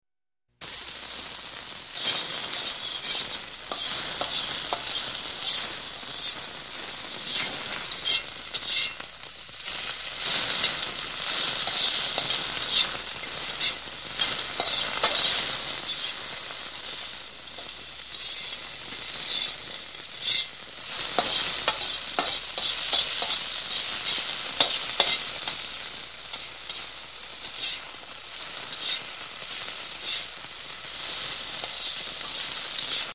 Назад в 3D звуки
3D звук , слушать только в наушниках (иначе эффекта ни будет ) , желательно в компьютерных , итак включаем , закрываем глаза , расслабляемся , слушаем ! Кто то че то готовит, точнее жарит .